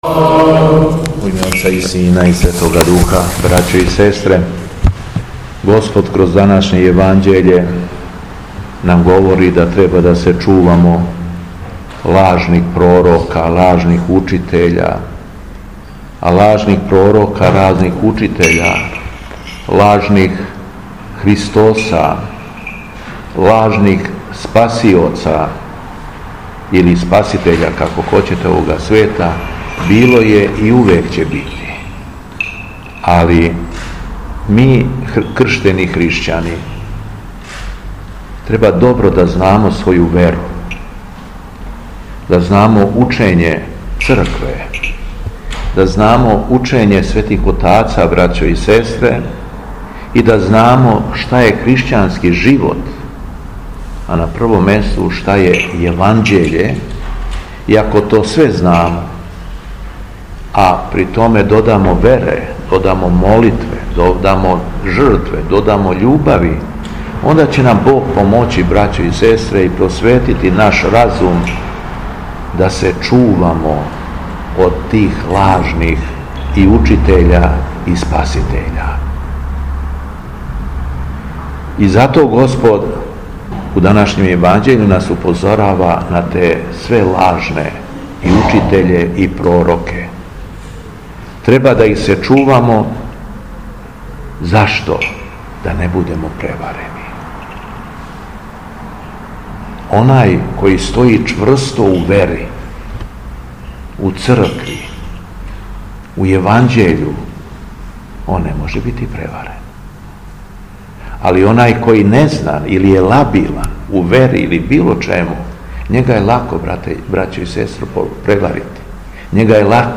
Беседа Његовог Преосвештенства Епископа шумадијског г. Јована
После прочитаног јеванђелског зачала преосвећени владика Јован се обратио верном народу беседом: